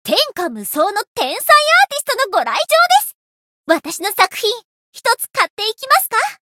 灵魂潮汐-叶月雪-人偶初识语音.ogg